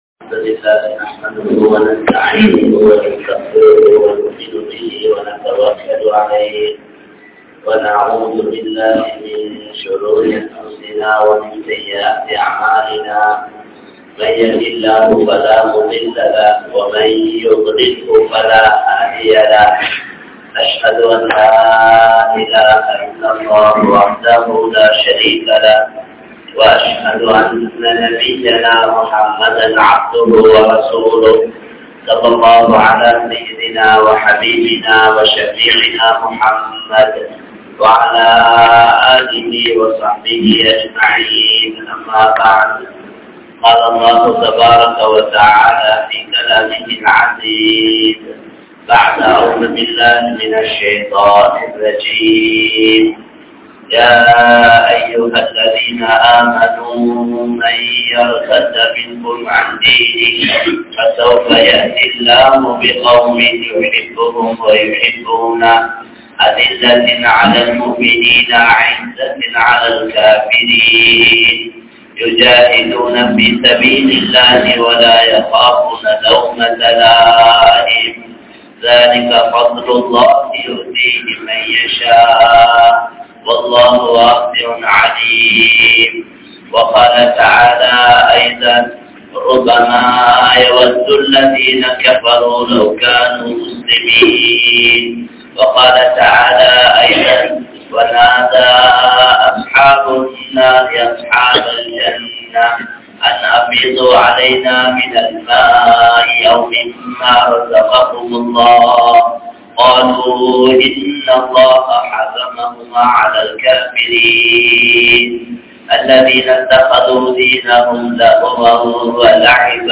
Paavaththai Vittu Vidungal (பாவத்தை விட்டு விடுங்கள்) | Audio Bayans | All Ceylon Muslim Youth Community | Addalaichenai